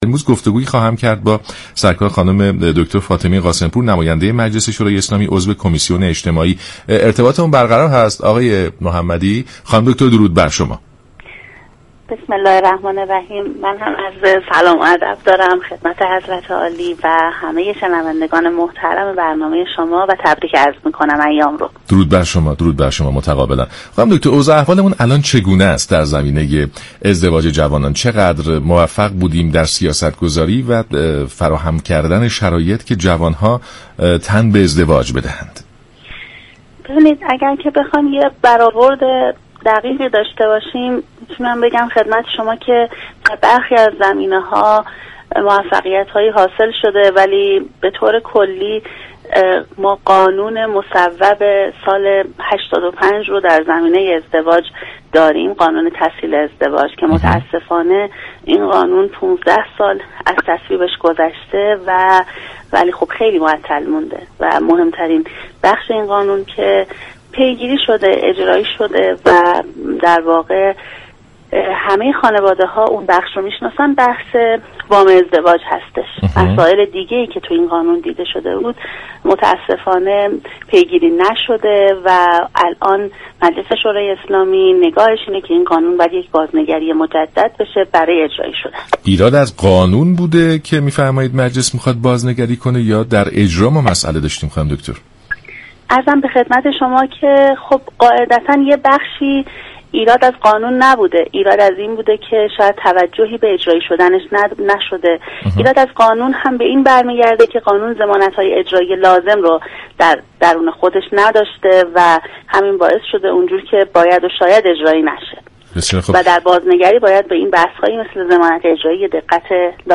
به گزارش شبكه رادیویی ایران، دكتر فاطمه قاسم پور عضو كمیسیون اجتماعی مجلس در برنامه سلام صبح بخیر رادیو ایران به ازدواج جوانان پرداخت و در پاسخ به این پرسش كه سیاستگذاری های اعمال شده در این حوزه چقدر موفق عمل كرده است، گفت: در حوزه ازدواج جوانان اگرچه در برخی از زمینه ها موفقیت هایی حاصل شده اما این مقدار كافی نیست.